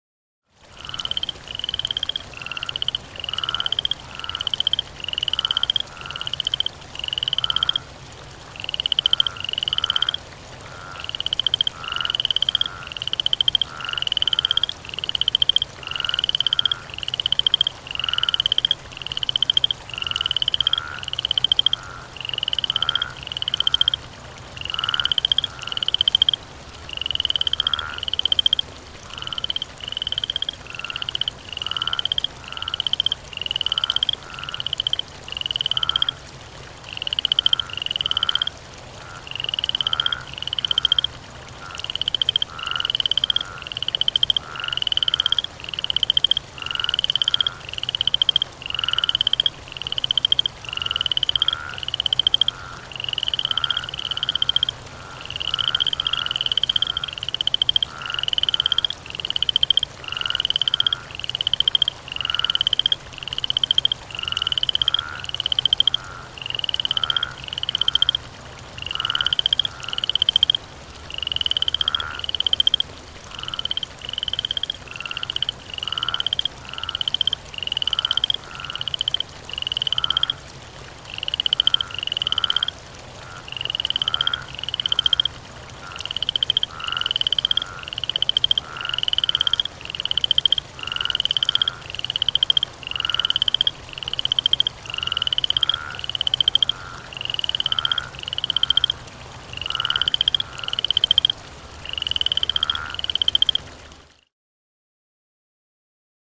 Frog Pond With Close Perspective Croaks And Chirps With Steady Running Stream.